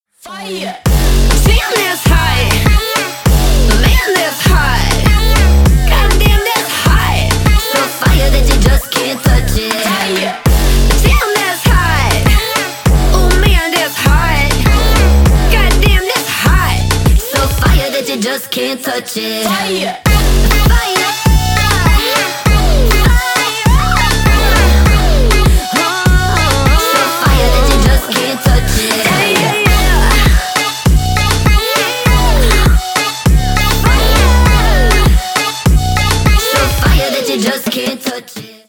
Поп Музыка
клубные # громкие